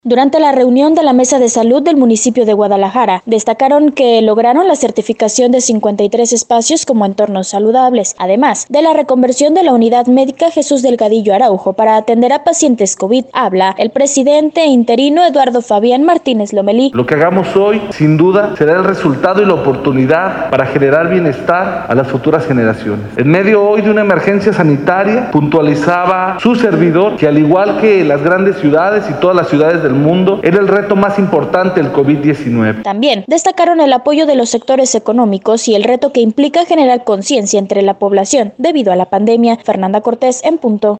Durante la reunión de la Mesa de Salud del municipio de Guadalajara, destacaron que el municipio lograron la certificación de 53 espacios como entornos saludables, además, de la reconversión de la unidad médica Jesús Delgadillo Araujo, para atender a pacientes Covid. Habla el Presidente Eduardo Fabián Martínez Lomelí: